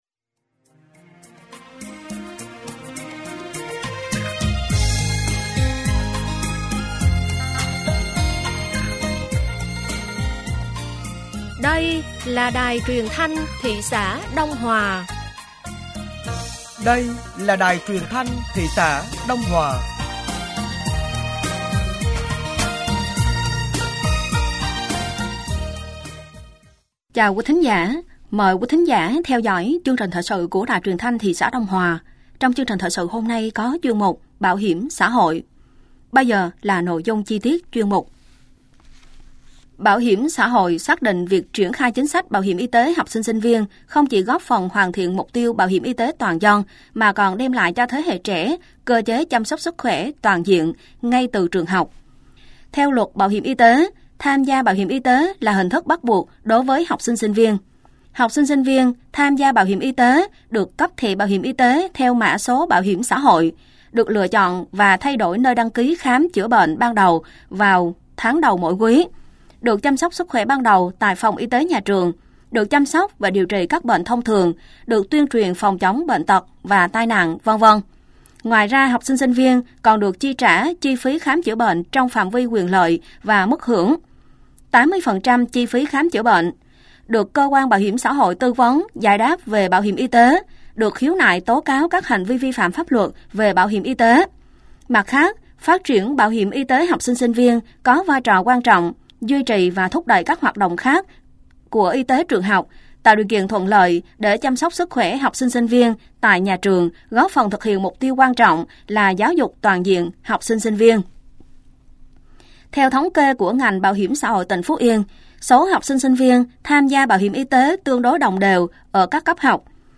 Thời sự tối ngày 08 và sáng ngày 09 tháng 9 nămn 2023